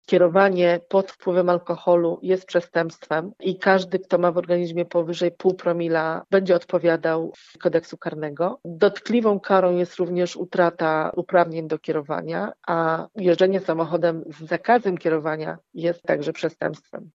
Jazda pod wpływem alkoholu będzie surowo karana – mówią policjanci: